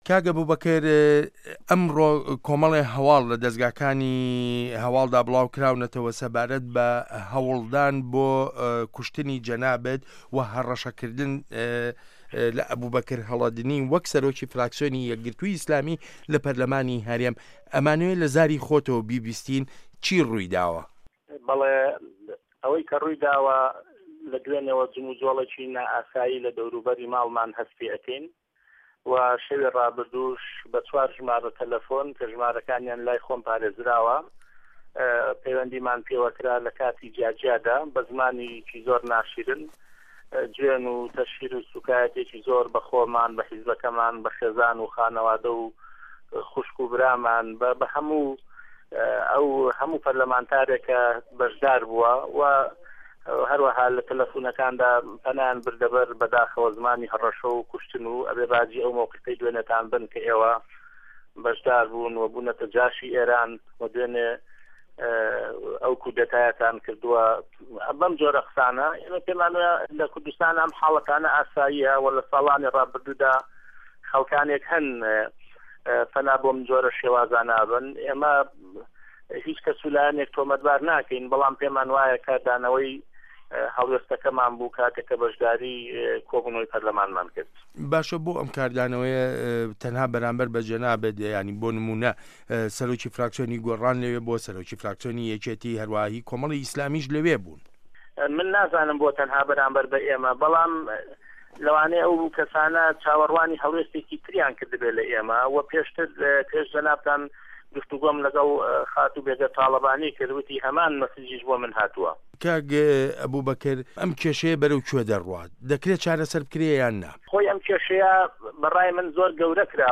وتووێژ له‌گه‌ڵ ئه‌بوبه‌کر هه‌ڵه‌دنی